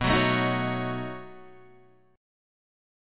B9 Chord